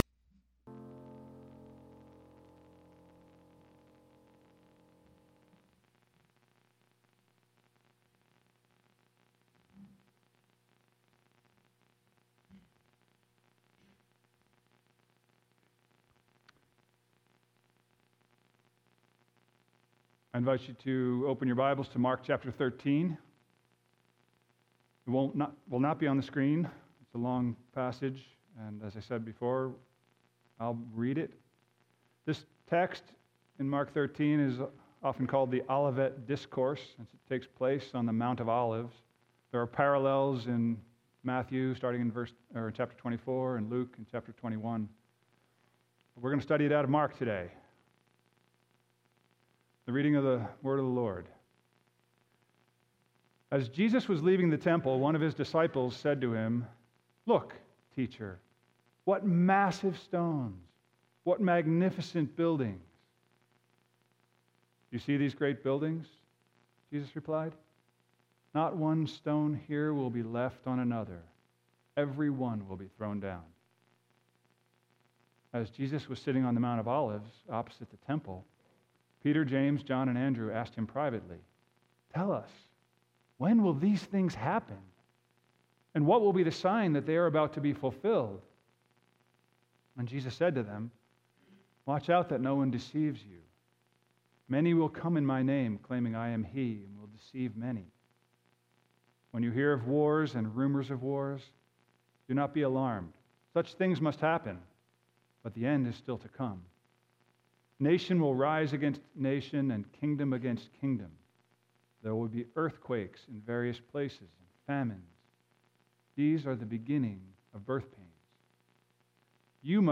Sermons at New Life Christian Reformed Church